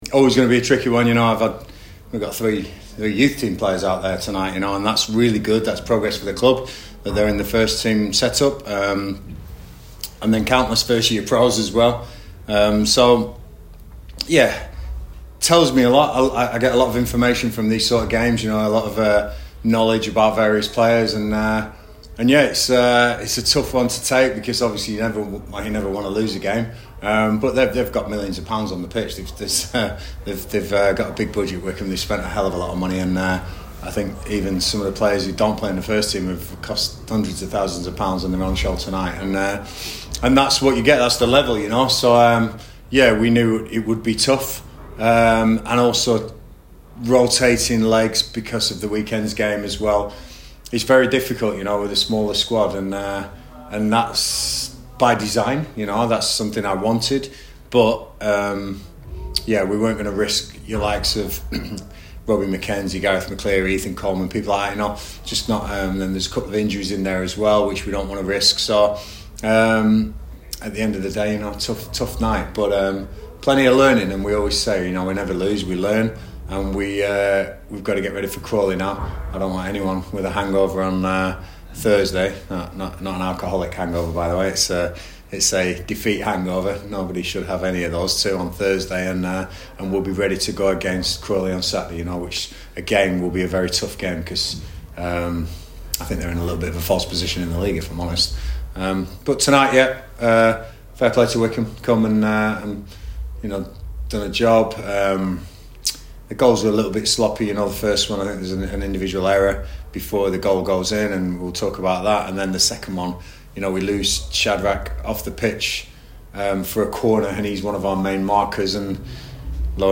LISTEN: Gillingham manager Gareth Ainsworth reacts to their EFL Trophy defeat to Wycombe Wanderers - 12/11/2025